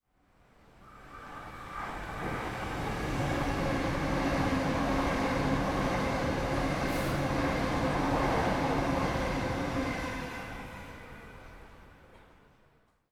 Added a new intro and main menu ambient sounds
train_pass2.ogg